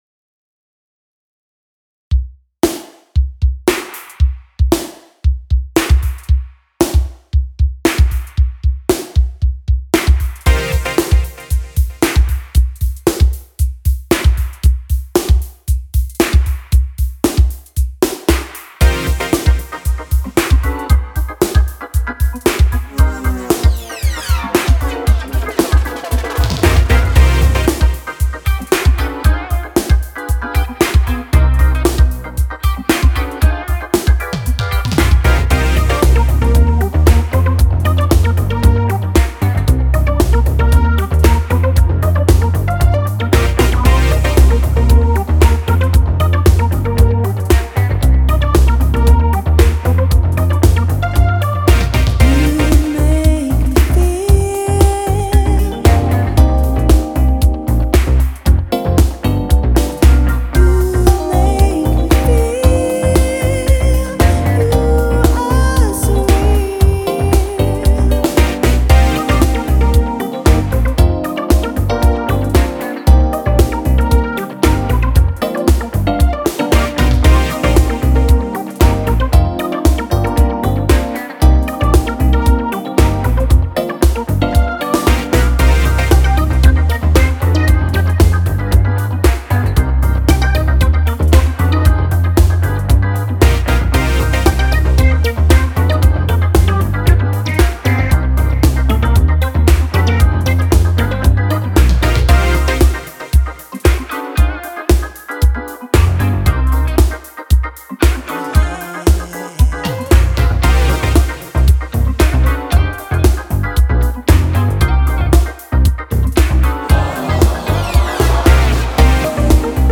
115bpm
Microtonic
Dexed : FM multi platform/multi format plugin